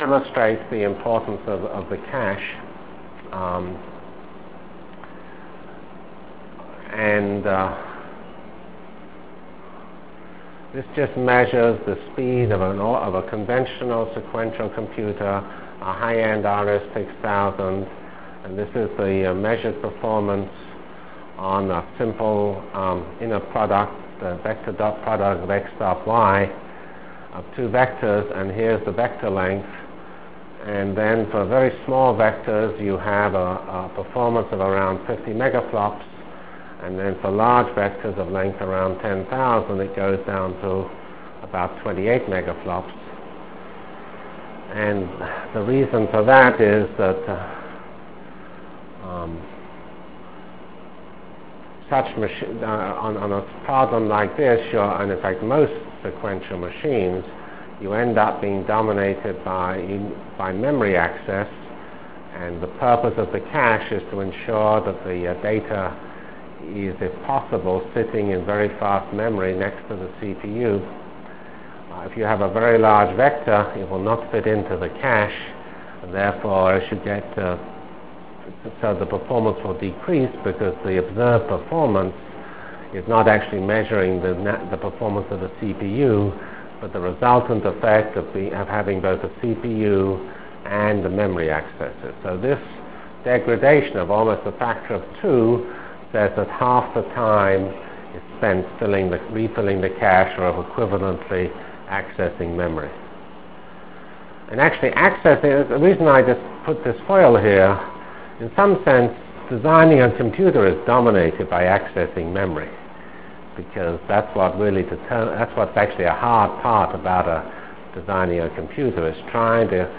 Delivered Lectures of CPS615 Basic Simulation Track for Computational Science -- 10 September 96.